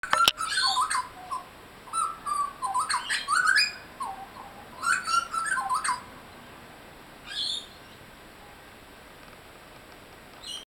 Butcher Bird Singing